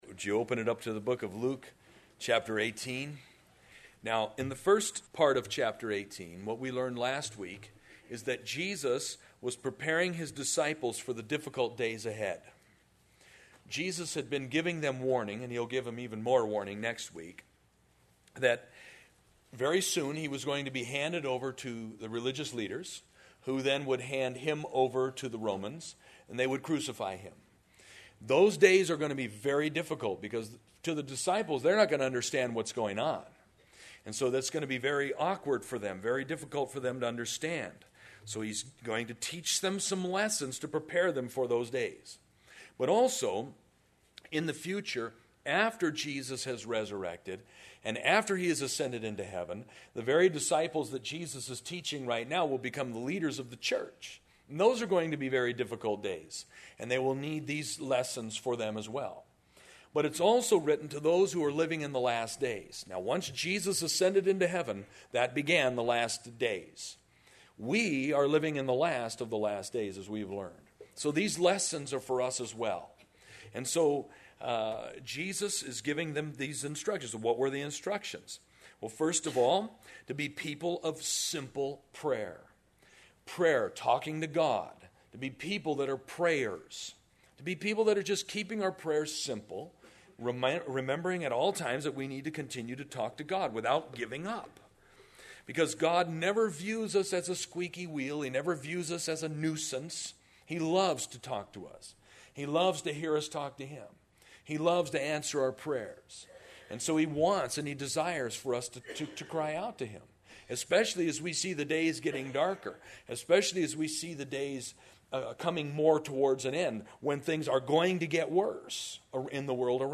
Saturday Morning Devotion